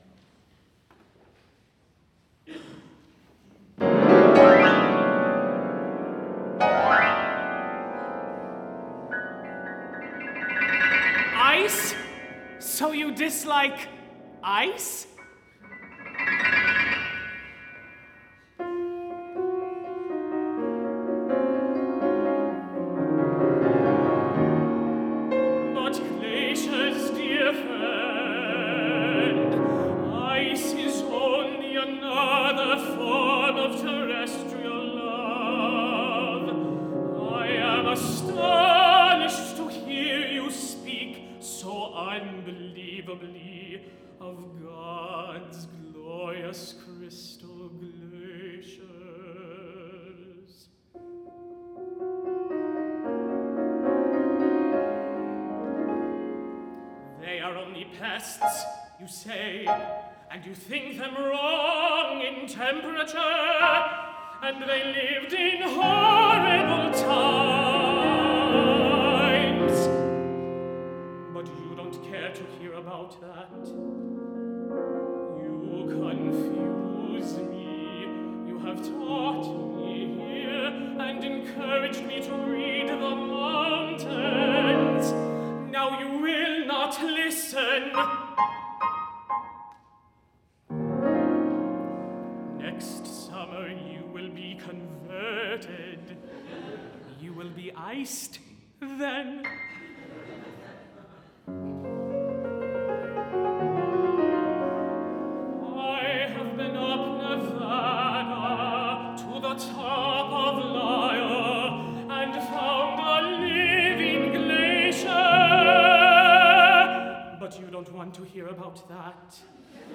for High Voice and Piano (2014)